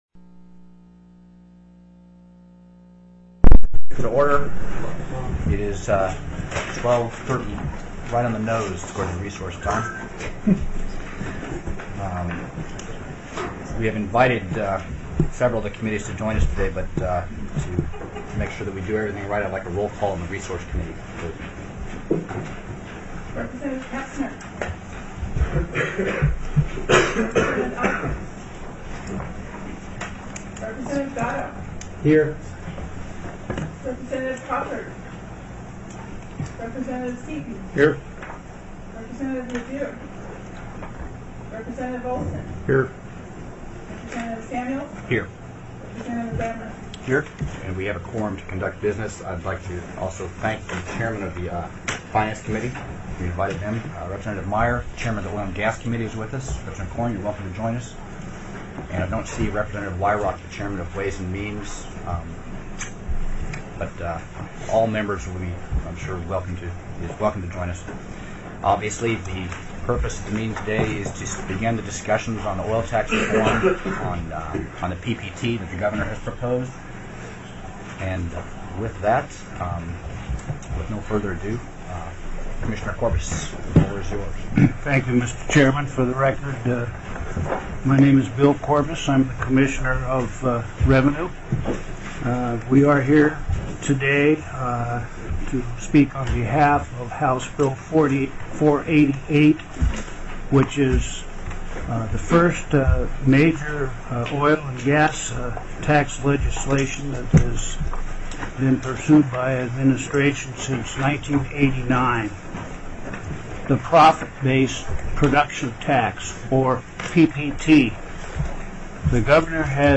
TELECONFERENCED
Presentation by the Administration